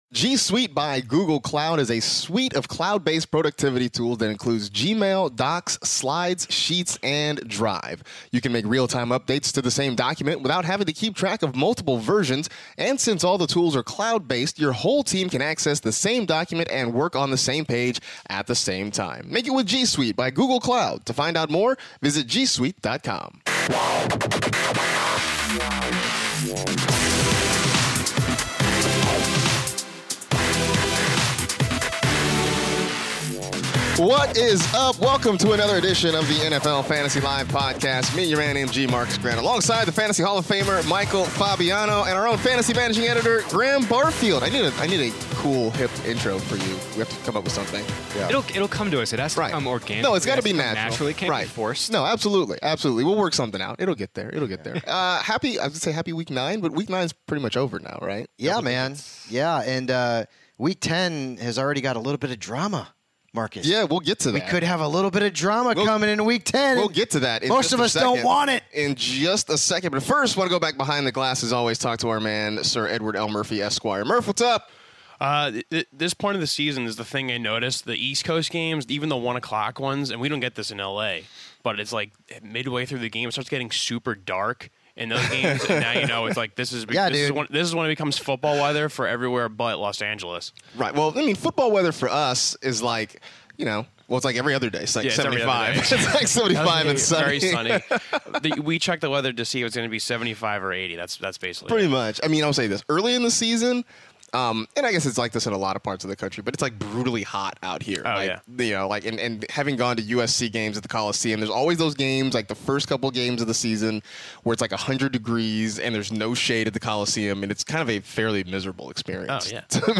are in studio to recap everything fantasy football for Week 9! The group starts off with the biggest news headlines like A.J. Green missing a few weeks due to his toe and Le'Veon Bell cryptically Tweeting out his return (3:30). Next, the guys go through 9 questions after Week 9 such as should we worry about Mark Ingram and is O.J. Howard an elite tight end? (13:10).